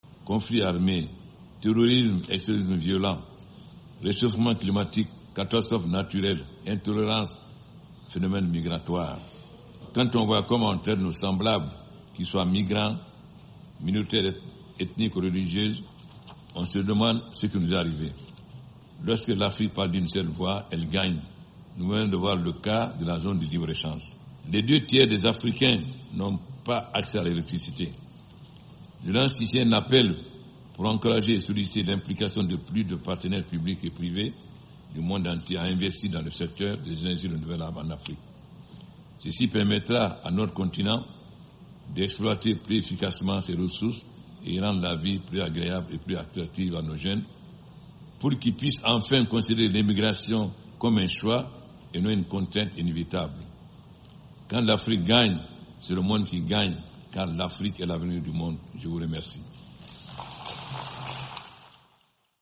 Extrait du discours du président guinéen Alpha Condé à la tribune de l’assemblée générale de l’Onu à New York le 25 septembre 2019.